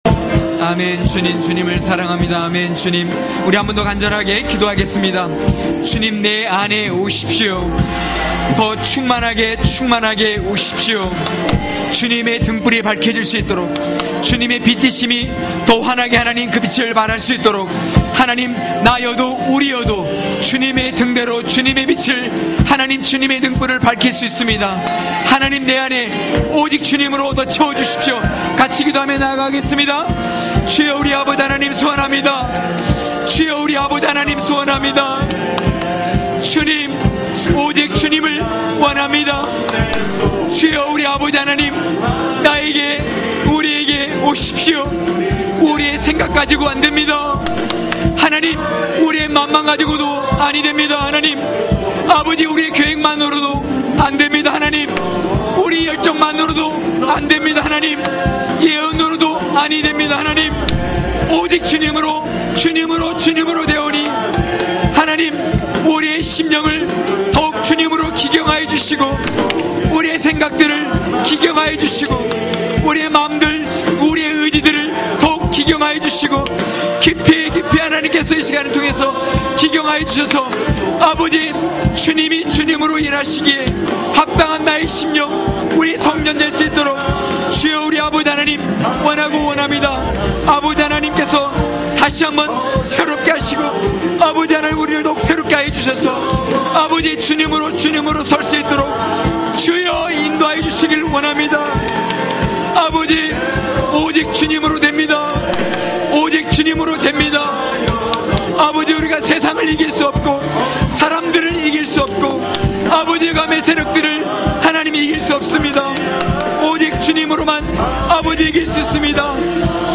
강해설교 - 7.성령의 흐름을 타라!!(느4장1~8절).mp3